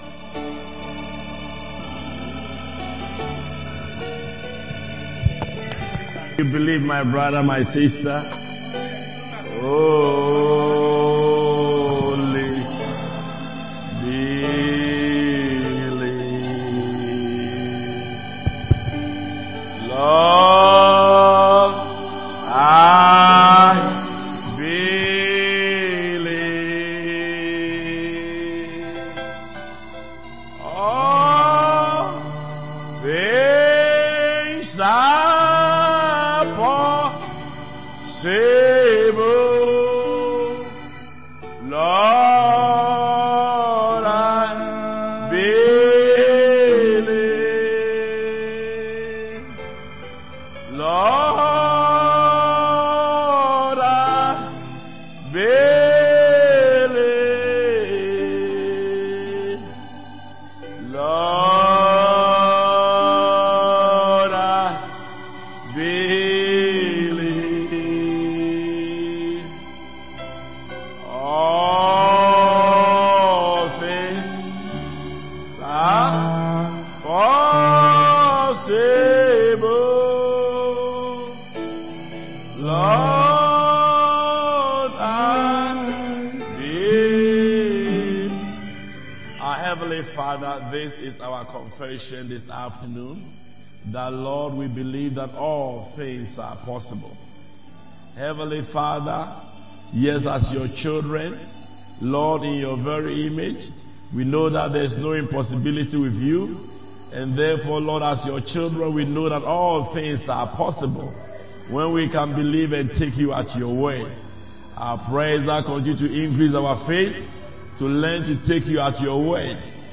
Sunday Afternoon Service 21-09-25